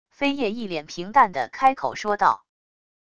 非夜一脸平淡的开口说道wav音频生成系统WAV Audio Player